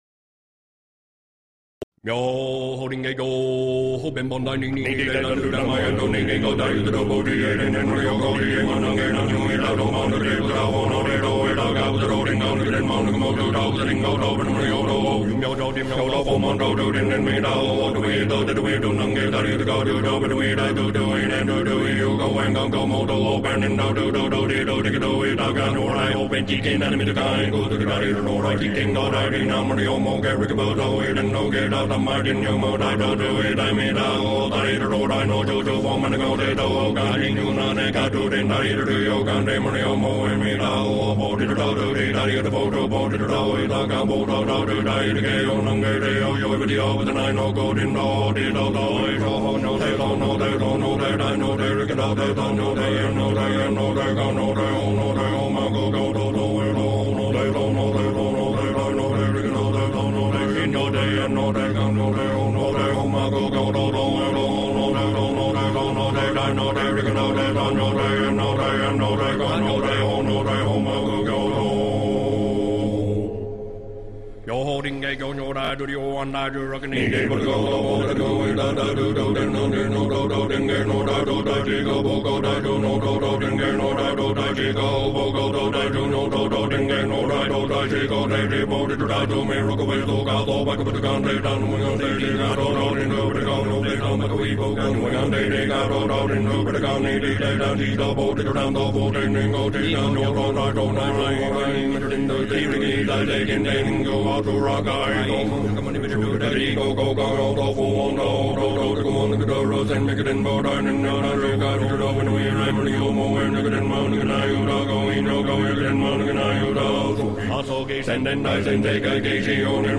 Listen to Tibetan Monks chanting
Nam Ryoho Renge Kyo with overtones